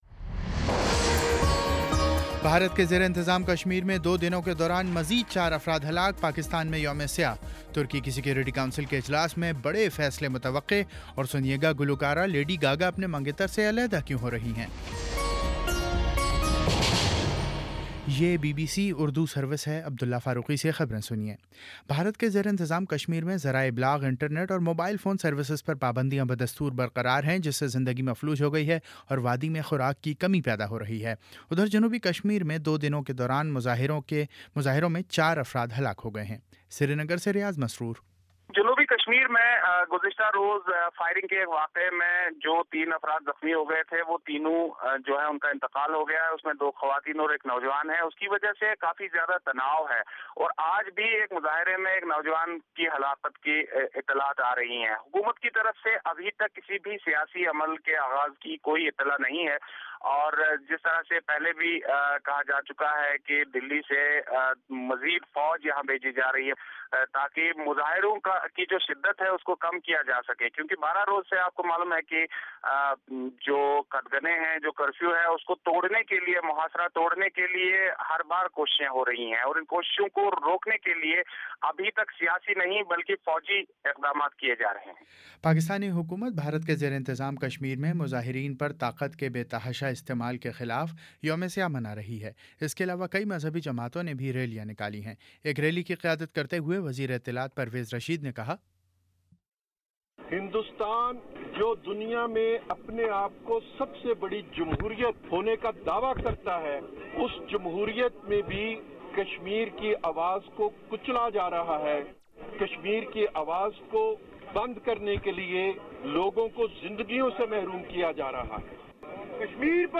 جولائی 0 2: شام چھ بجے کا نیوز بُلیٹن